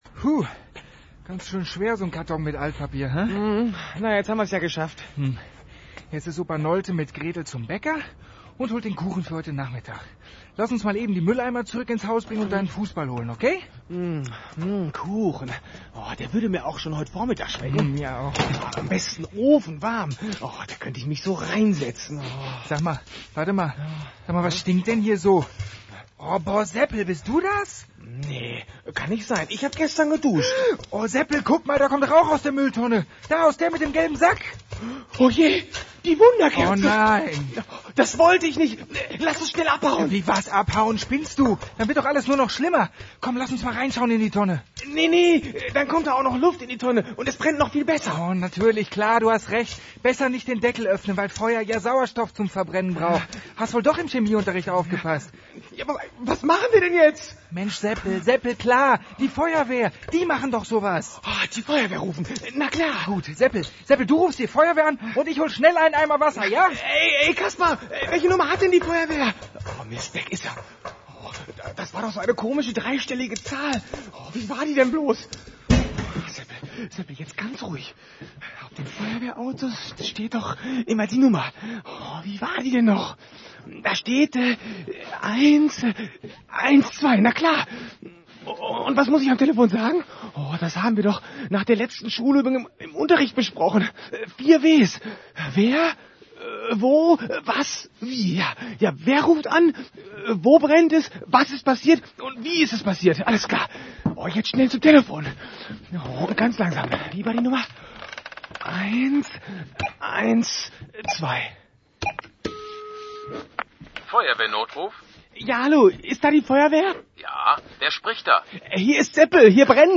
Das Bühnenstück “Feuerteufel und der gelbe Sack” unserer Jugendfeuerwehr-Puppenbühne wurde 1998 als Hörspiel bearbeitet und durch die LAVA-Studios in Paderborn mit Berufsschauspielern produziert. In diesem Stück geht es um Müllsortierung und um eine brennende Wunderkerze, die Seppel aus Angst vor der Hausmeisterin in die Tonne mit dem gelben Sack wirft.
Hoerspiel.mp3